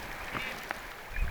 tavi lennossa naaraan ääni
tavi_tietaakseni.mp3